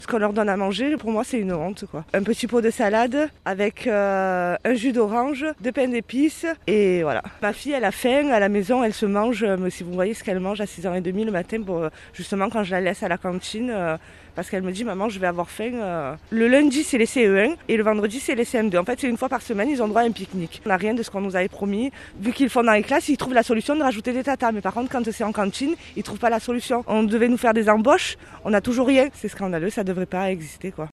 Reportage à Marseille